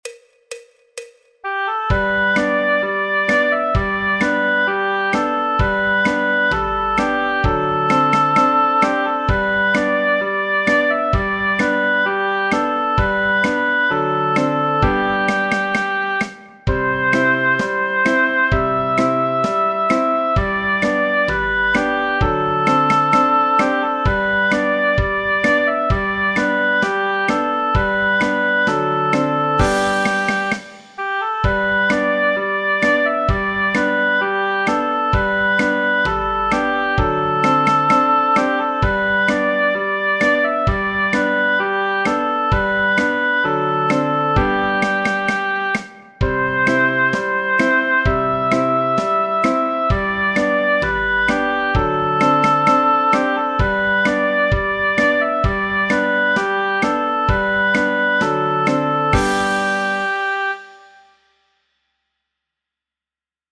El MIDI tiene la base instrumental de acompañamiento.
Folk, Popular/Tradicional